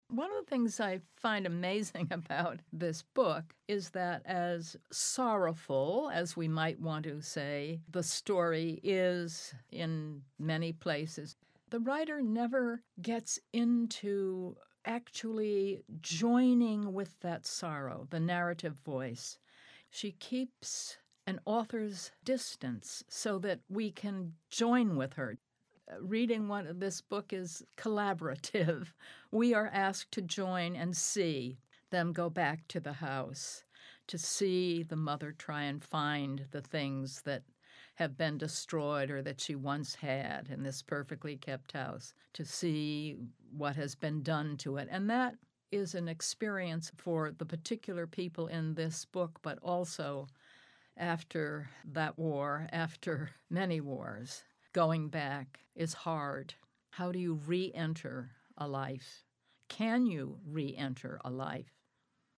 Writer Maureen Howard talks about Julie Otsuka's narrative voice.